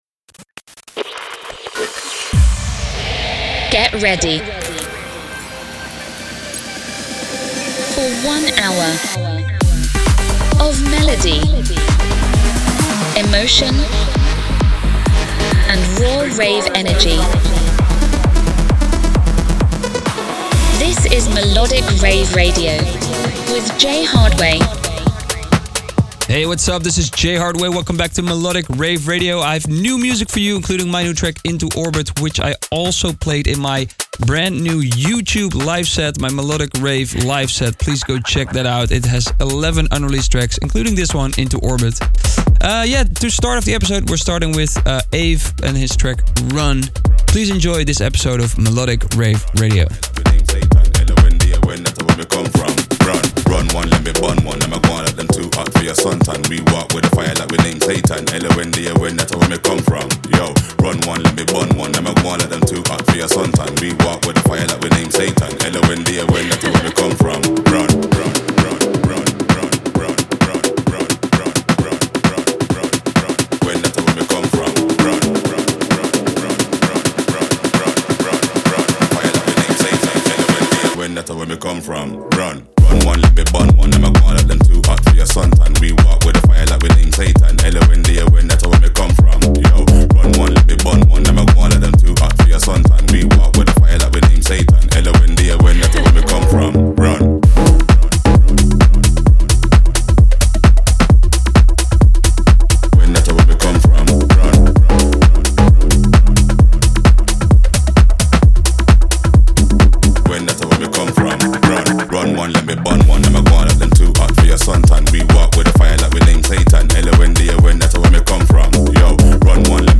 music DJ Mix in MP3 format
Genre: Electro House